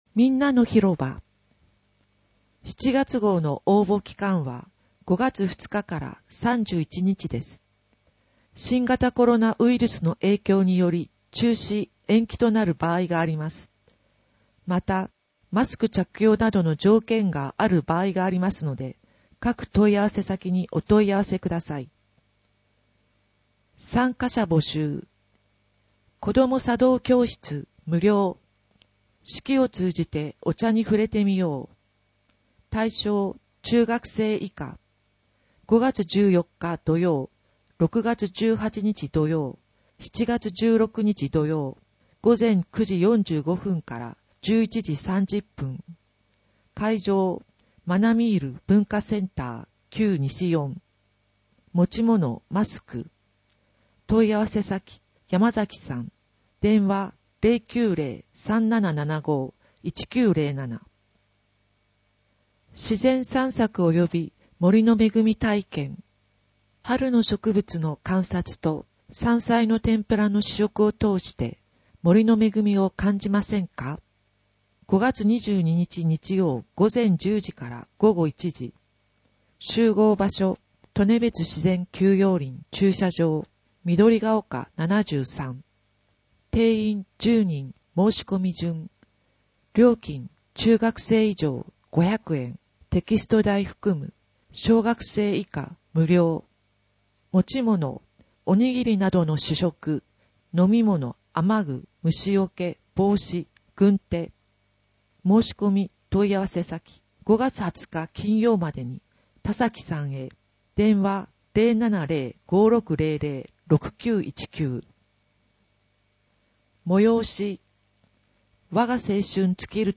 声の広報MP3版は、岩見沢さつきの会にご協力をいただき、録音しているものです。
声の広報（MP3）